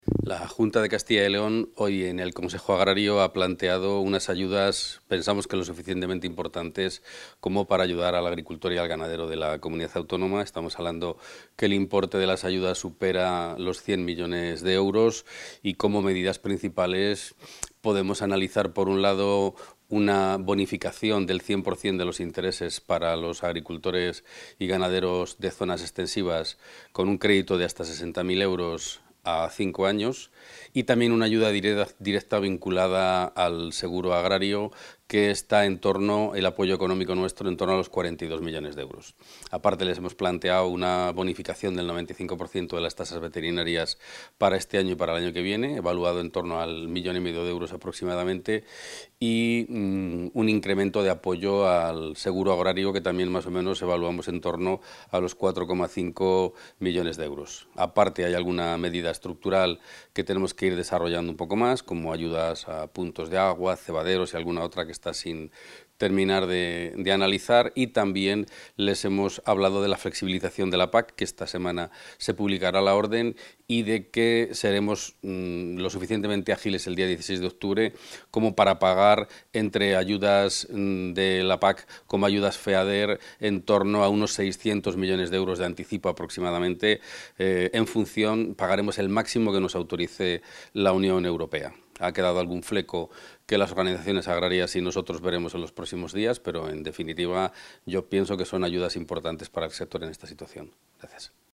Declaraciones del consejero.
Material audiovisual de la reunión del Consejo Agrario de Castilla y León Contactar Escuchar 20 de junio de 2023 Castilla y León | Consejería de Agricultura, Ganadería y Desarrollo Rural El consejero de Agricultura, Ganadería y Desarrollo Rural, Gerardo Dueñas, ha presidido hoy la reunión del Consejo Agrario de Castilla y León.